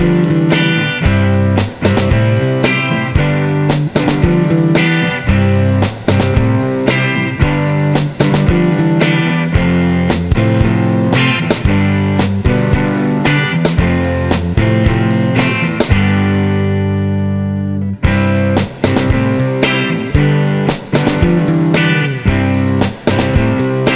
no Backing Vocals Pop (1960s) 2:41 Buy £1.50